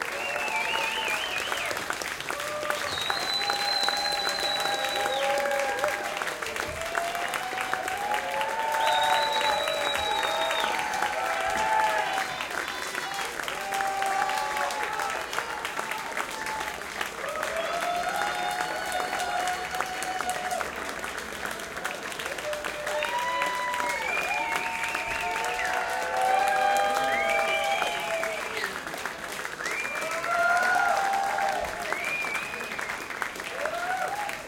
crowdCheerLoop.ogg